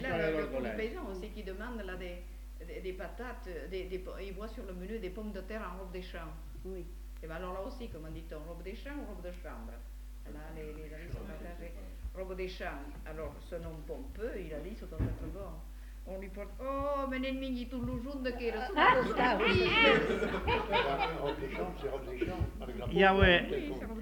Lieu : Uzeste
Genre : conte-légende-récit
Effectif : 1
Type de voix : voix de femme
Production du son : parlé
Classification : récit anecdotique